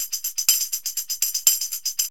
TAMB LP 122.wav